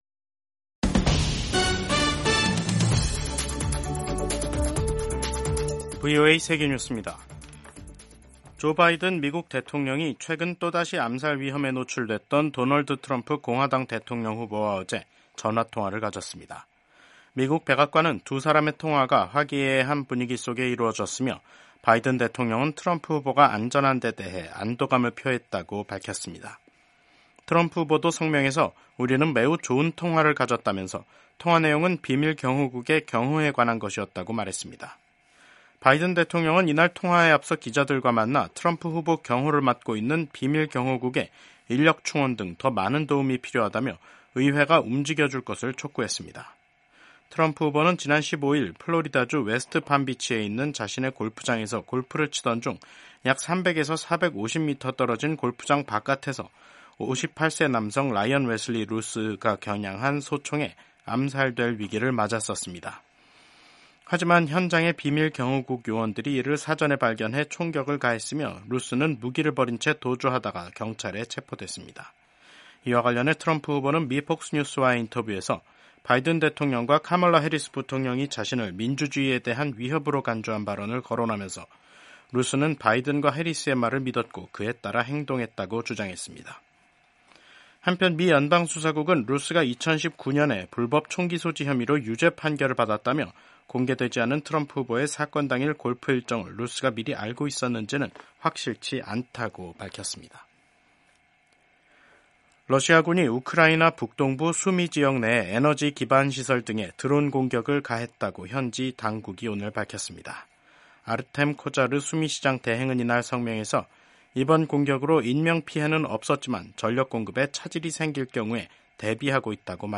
세계 뉴스와 함께 미국의 모든 것을 소개하는 '생방송 여기는 워싱턴입니다', 2024년 9월 17일 저녁 방송입니다. 미국 공화당 대통령 후보인 도널드 트럼프 전 대통령을 암살하려고 시도한 사람이 사건 현장 주변에서 약 12시간 동안 머무른 것으로 드러났습니다. 블라디미르 푸틴 러시아 대통령이 현역 병력을 150만 명까지 늘리라고 지시했습니다. 홍콩에서 새로운 국가보안법에 따라 처음으로 유죄 판결을 받은 사례가 나왔습니다.